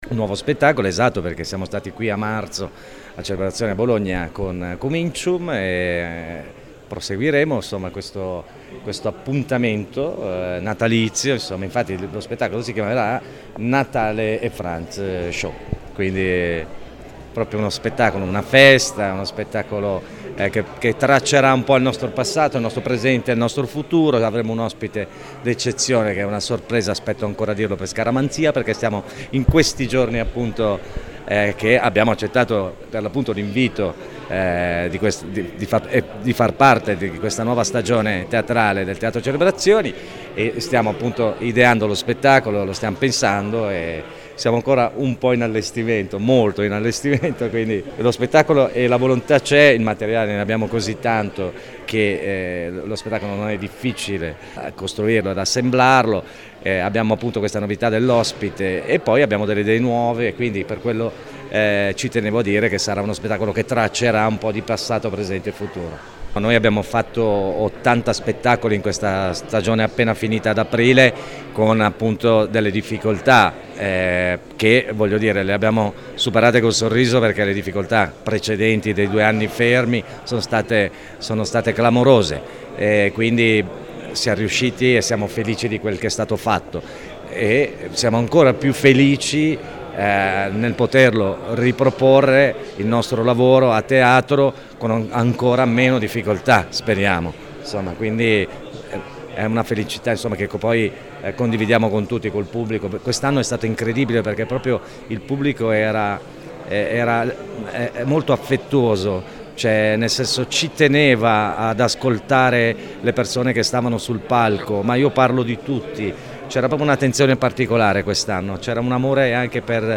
Il 9 e il 10 dicembre sarà la volta di Ale e Franz con lo spettacolo Natale & Franz Show. Sentiamo Ale: